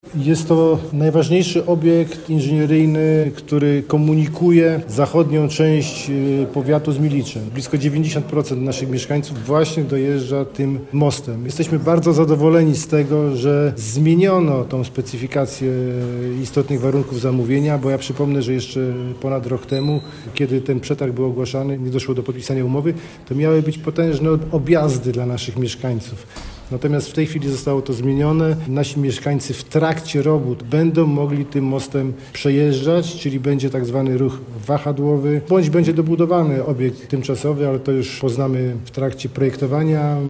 Łukasz Rokita, Starosta Milicki podkreśla, że cieszy go umowa, która uwzględnia warunki przemieszczania się mieszkańców – bez utrudnień i ogromnych objazdów.